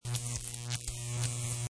Kho Âm thanh Máy móc (Machinery Sound Effects)
Tất cả các file âm thanh đều được xử lý lọc nhiễu, đảm bảo độ trong trẻo để bạn có thể kéo-thả trực tiếp vào timeline mà không cần chỉnh sửa quá nhiều.